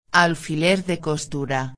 28/11/2011 Alfiler Alfinete •\ [al·fi·ler] \• •\ Substantivo \• •\ Masculino \• Significado: Peça metálica com que se prende roupa, pregando-a. Origem: Do espanhol antigo "alfilel" Exemplo com áudio: Alfiler de costura.